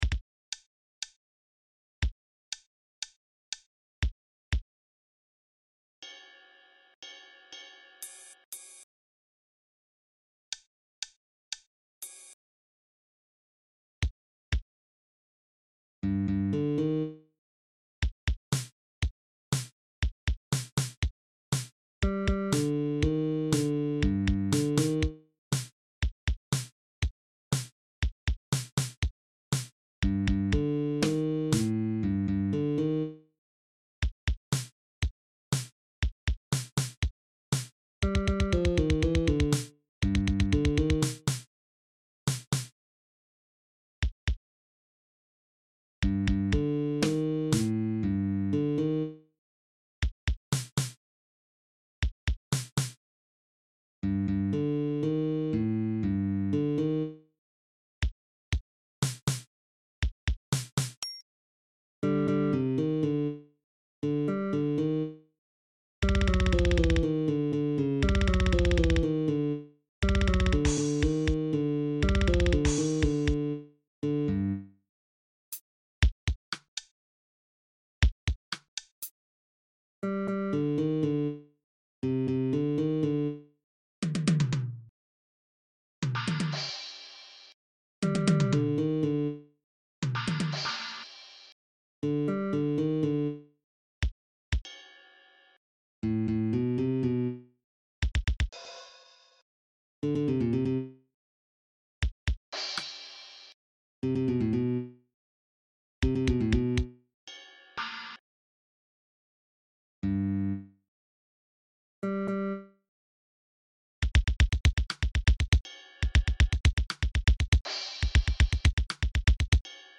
драм-ен-басс